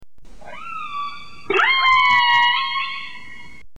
Orca - Pacific Northwest